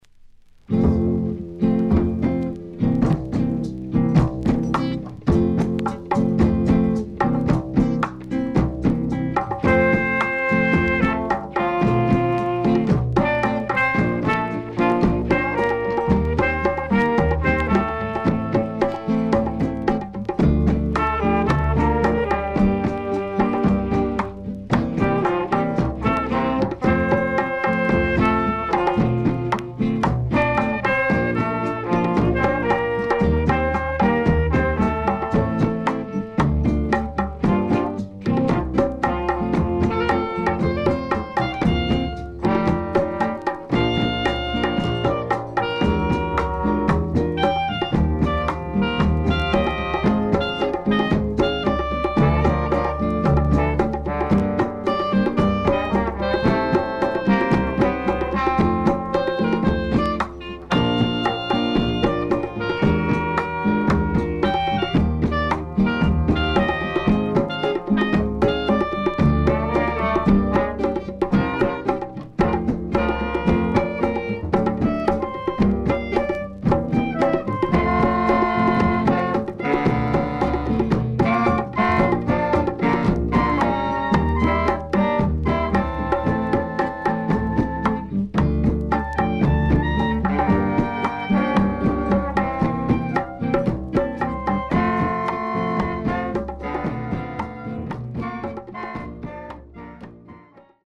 SIDE A:出だし小傷により少しプチパチノイズ入ります。